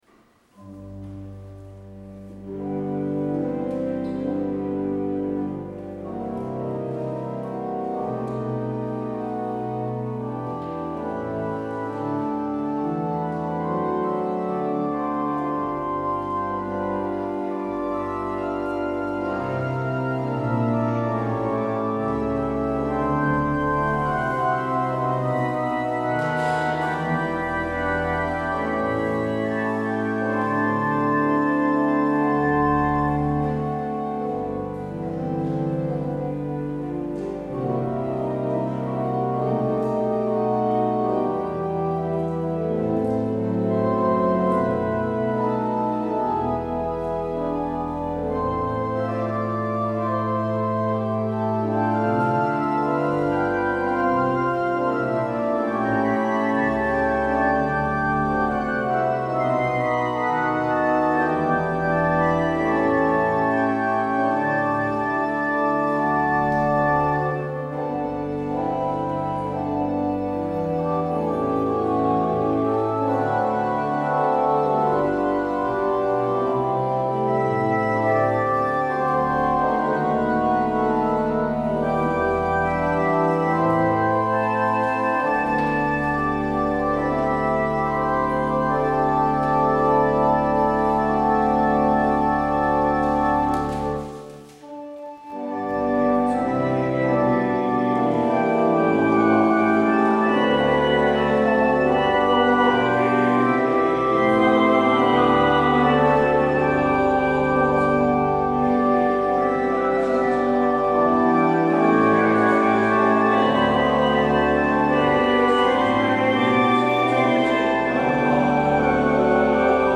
 Luister deze kerkdienst terug
Als openingslied: Lied 586 A (Ubi caritas) en het slotlied is Lied 791 (Liefde, eenmaal uitgesproken).